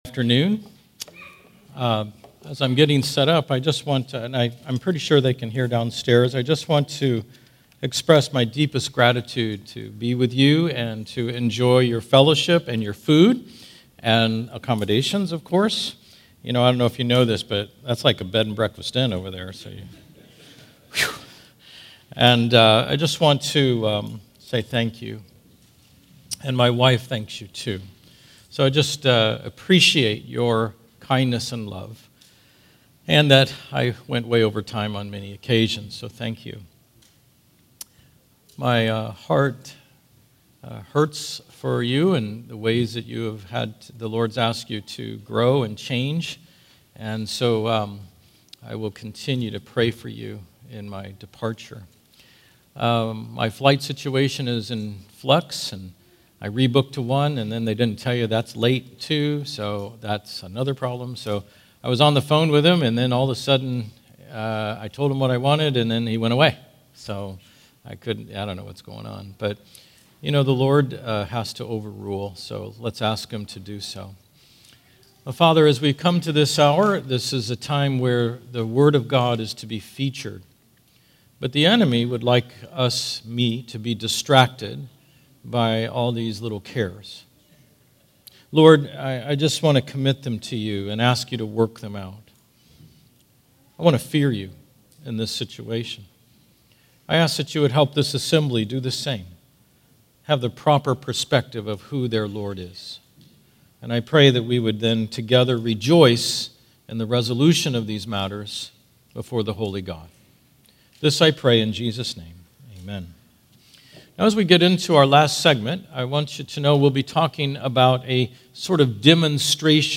All Sermons Fall Conference 2025